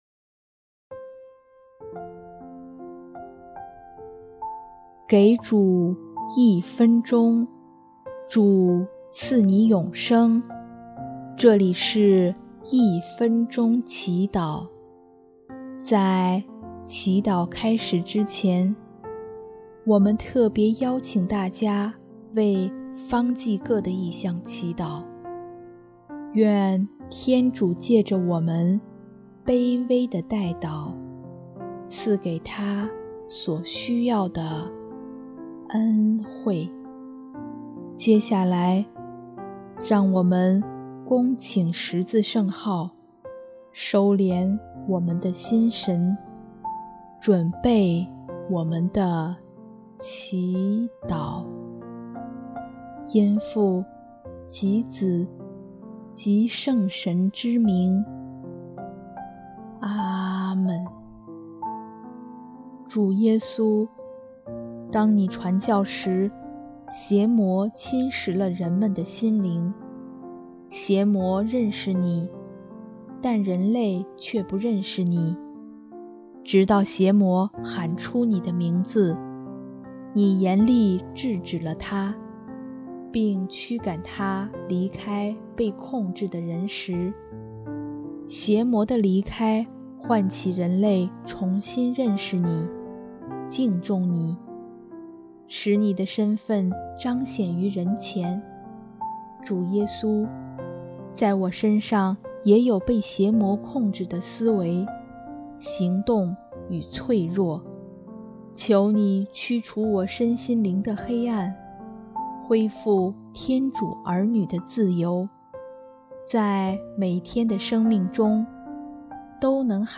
【一分钟祈祷】|9月3号 耶稣请驱除我身上的邪魔